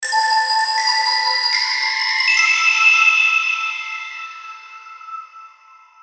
• Качество: 320, Stereo
без слов
инструментальные
волшебные
звонкие